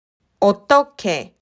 「ケ」が強い発音の「ケ」なので、日本語では「ッケ」と表記しますが、実際の発音は「オットケ」に近いので、ここでは併記します。